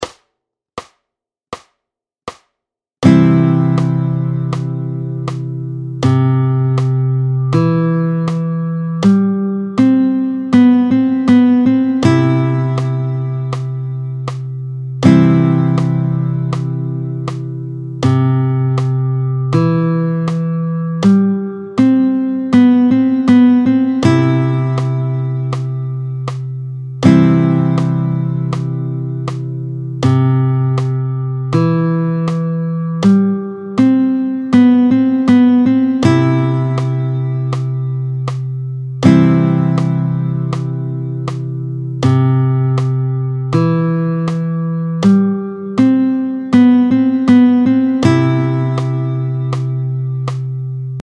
04-01 Lire une tablature, tempo 80 : 6 lignes représentant les 6 cordes, la MI (E) grave en bas